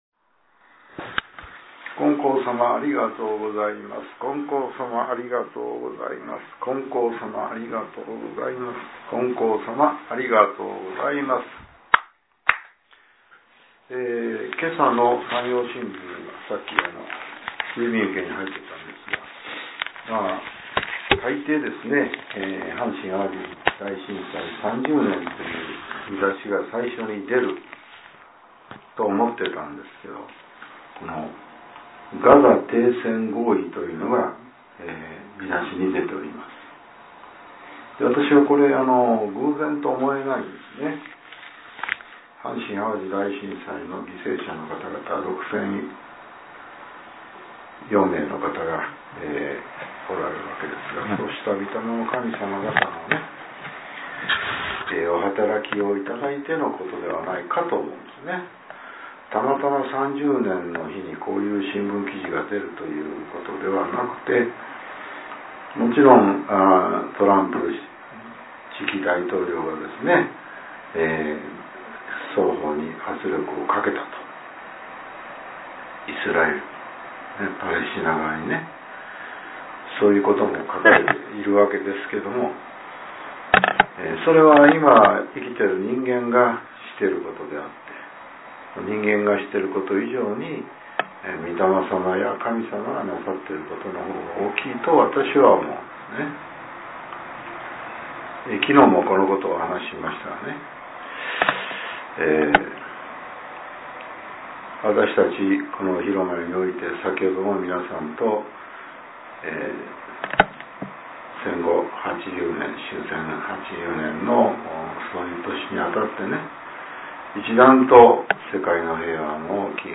令和７年１月１７日（朝）のお話が、音声ブログとして更新されています。